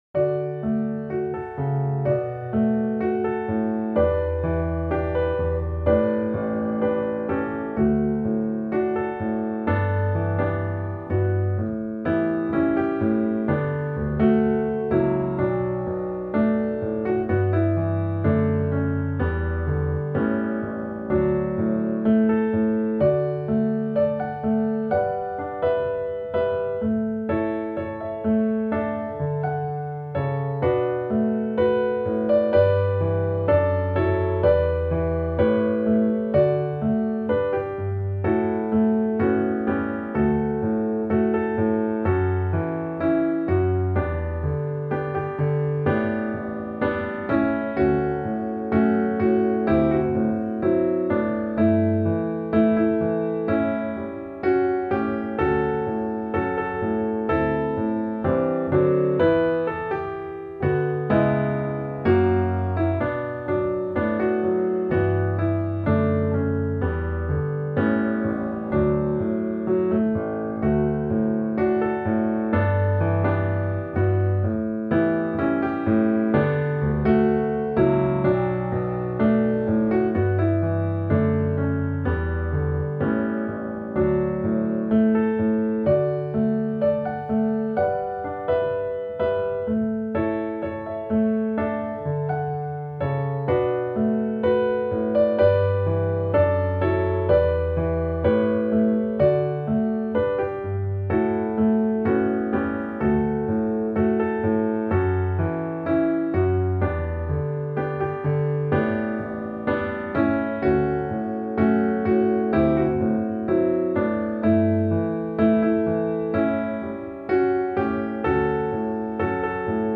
Piano Audio